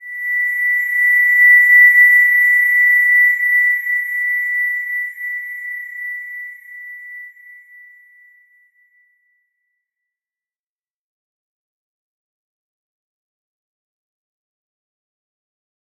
Slow-Distant-Chime-B6-f.wav